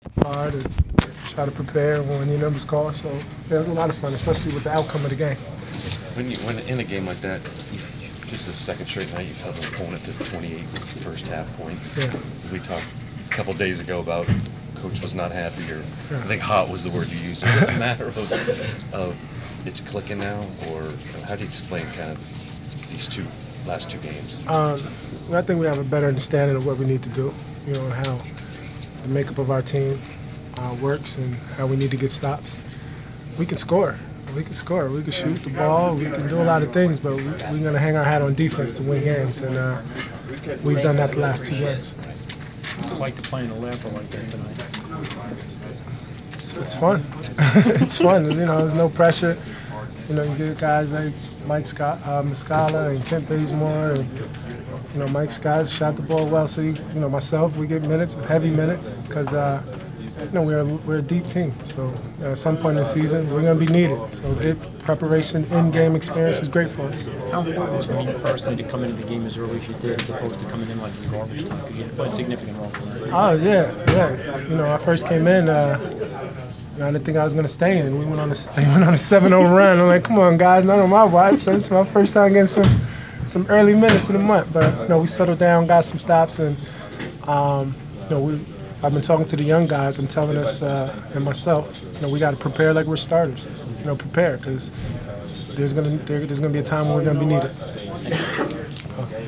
Inside the Inquirer: Interview with Atlanta Hawks’ Elton Brand (11/29/14)
We caught up with Atlanta Hawks’ forward Elton Brand following his team’s 105-75 home win over the Charlotte Hornets on Nov. 29. Brand discussed his extended playing time in the victory and the team’s overall focus at this stage of the season.